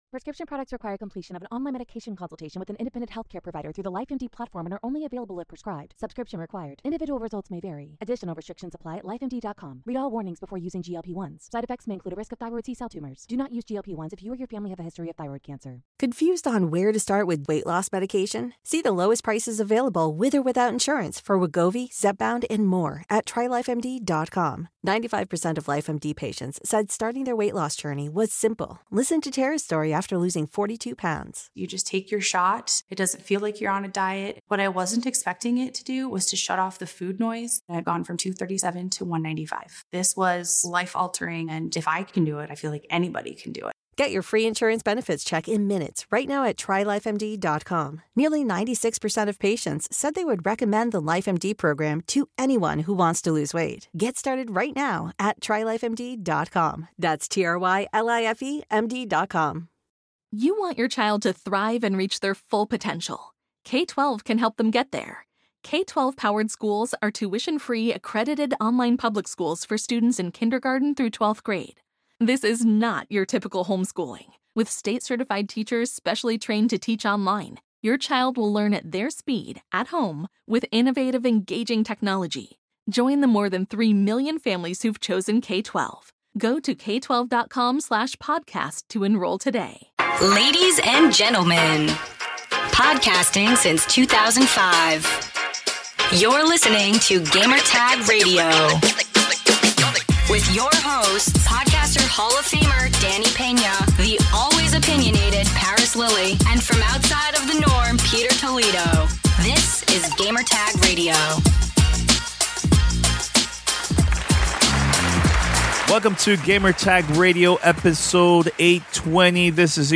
Game of the year 2018 roundtable discussion, top 5 games, honorable mentions and disappointing games.